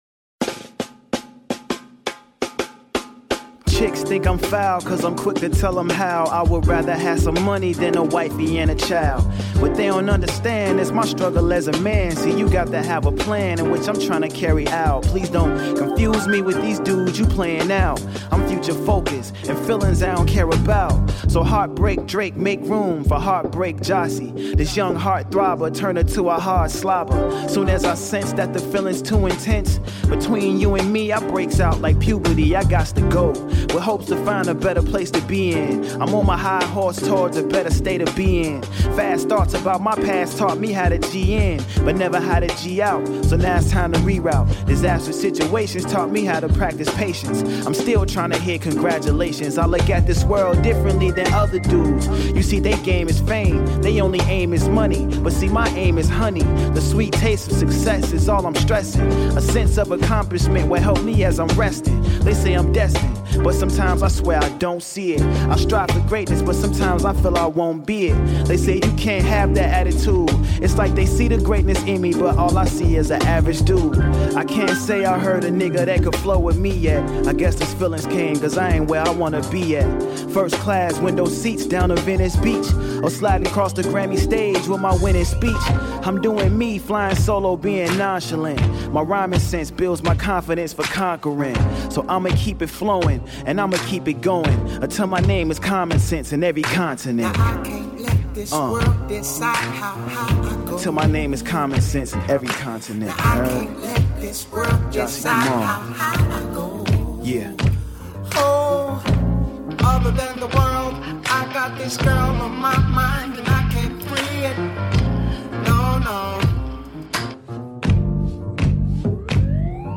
I love uncovering quality undercover rappers.